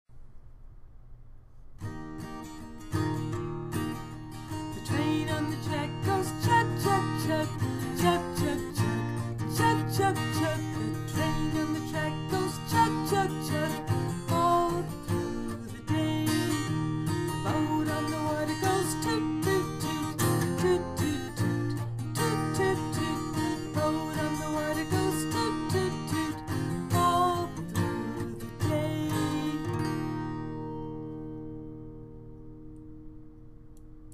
Tune: "The Wheels on the Bus"